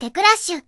Added Zundamon voicepack